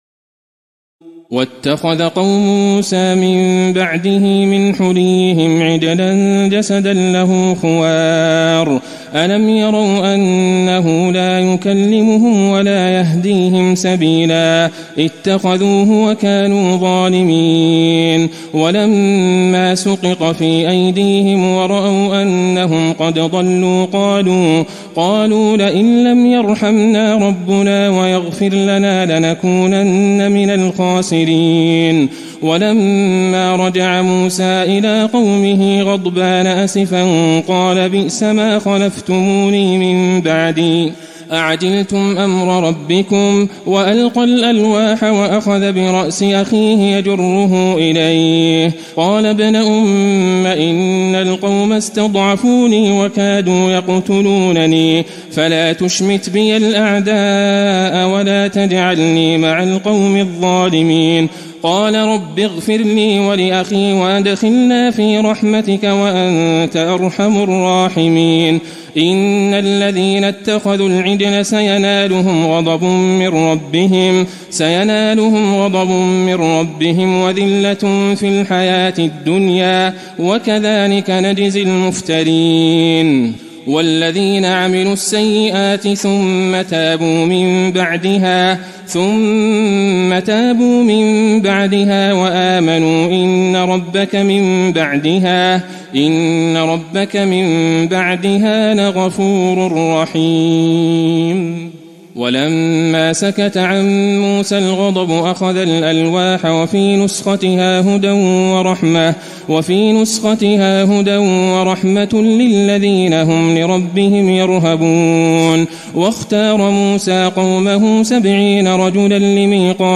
تراويح الليلة الثامنة رمضان 1435هـ من سورتي الأعراف (148-206) والأنفال (1-21) Taraweeh 8 st night Ramadan 1435H from Surah Al-A’raf and Al-Anfal > تراويح الحرم النبوي عام 1435 🕌 > التراويح - تلاوات الحرمين